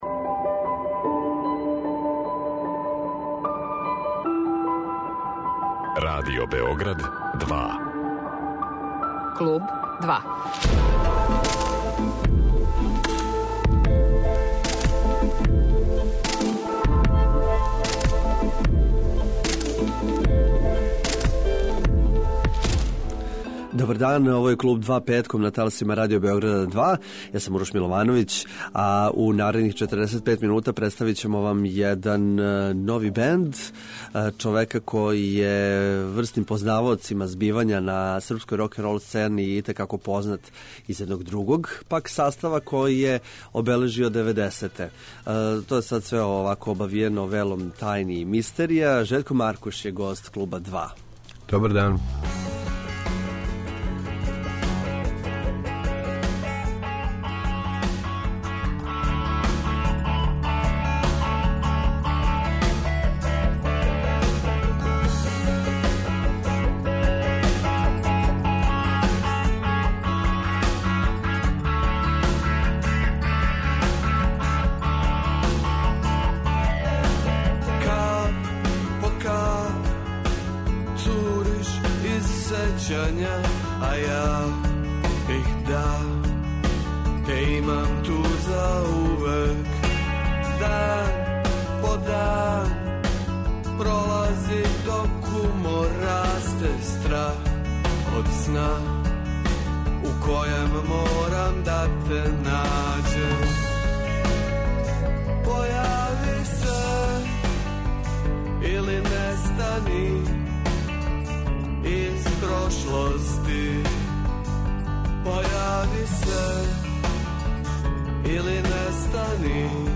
Гост емисије